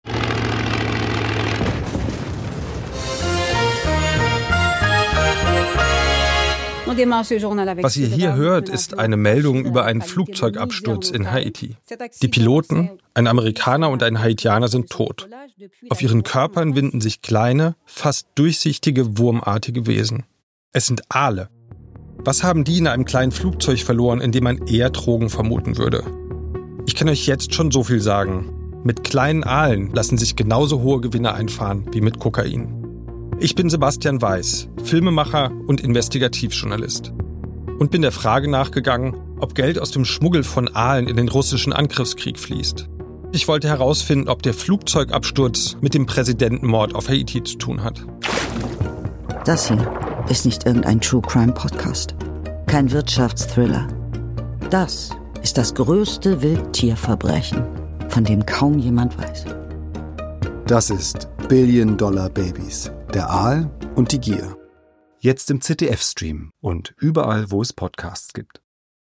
Im Podcast begleitet Katja Riemann als Stimme des Aals diese Reise durch eine verborgene Welt, erzählt von Mythos und Bedrohung, von der Faszination dieser uralten Spezies und von ihrem drohenden Verschwinden.
Mit der Stimme von Katja Riemann